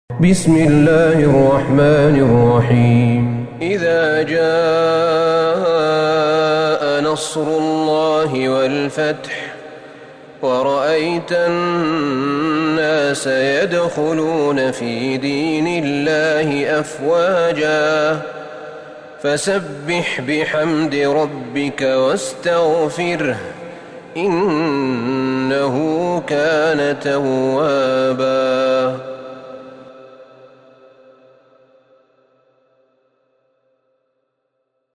سورة النصر Surat An-Nasr > مصحف الشيخ أحمد بن طالب بن حميد من الحرم النبوي > المصحف - تلاوات الحرمين